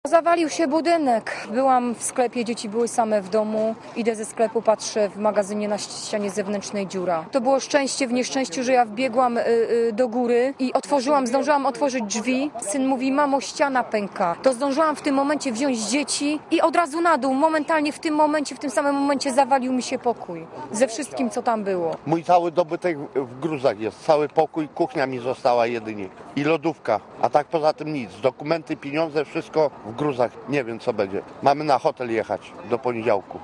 Komentarz audio (216Kb)